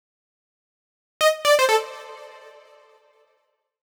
XTRA048_VOCAL_125_A_SC3.wav
1 channel